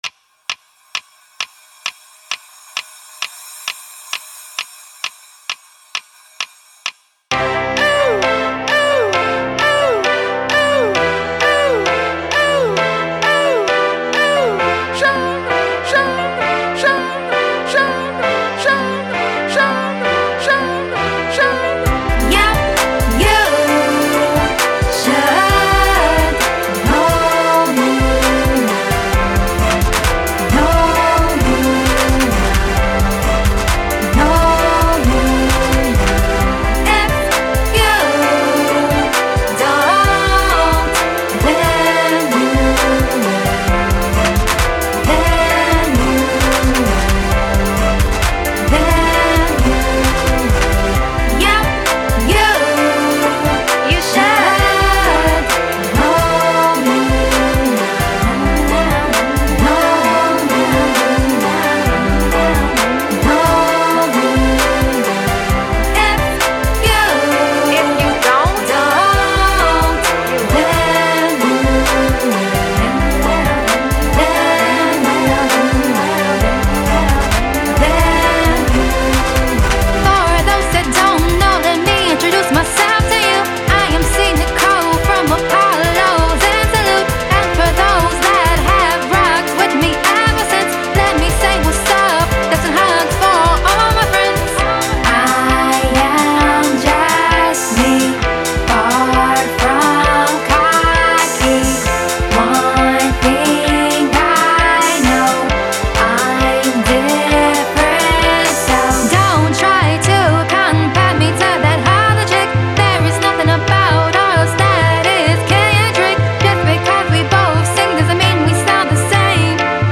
Genre: Urban Pop